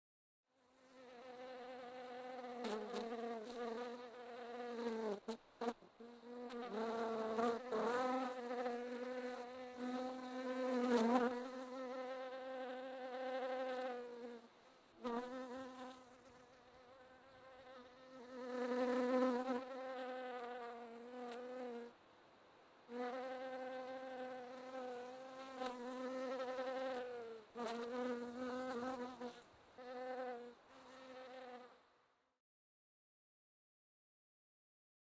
SND_mosquit_single_bee_01.ogg